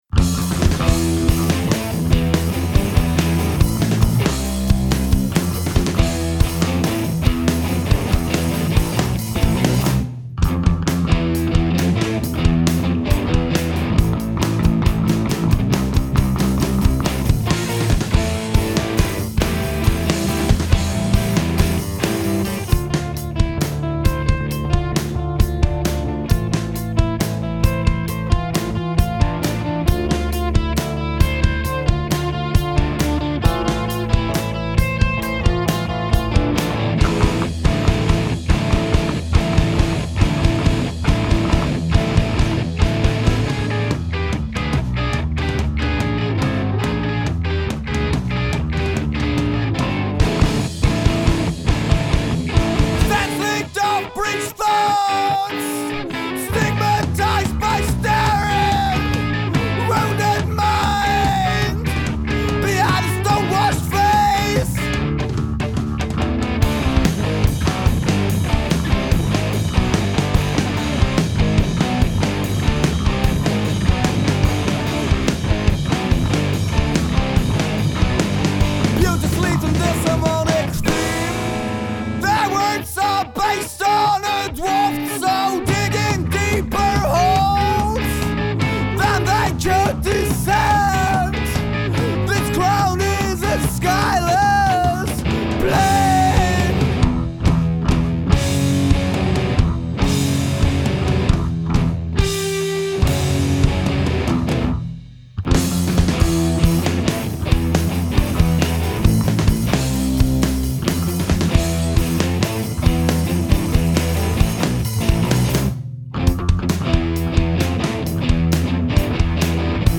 spielt Gitarre und singt
spielt Bass
spielt Schlagzeug